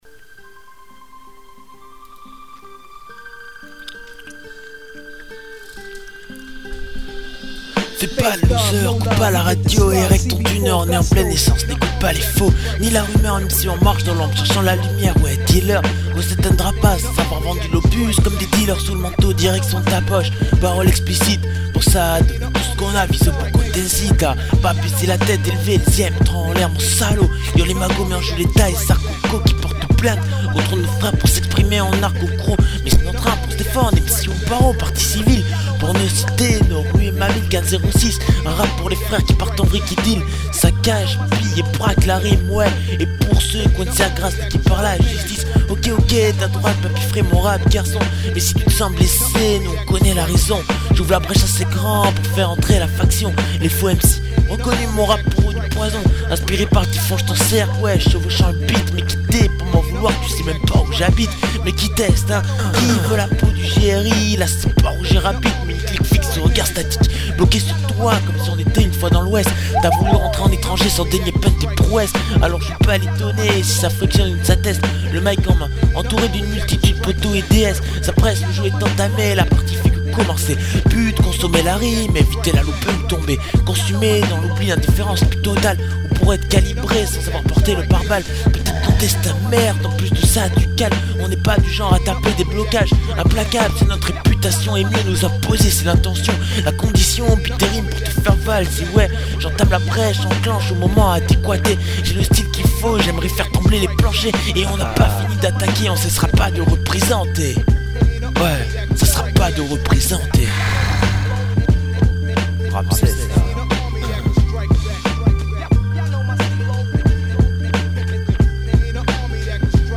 Nos trois rappeurs, s'entrainent dur à la rime entre novembre et janvier, vint alor leur premiers essais audio sur instrumentale empruntée, après tout, ce n'est que de l'entraînement...
freestyle
la rime est brute, tranchante et le flow est impressionnant.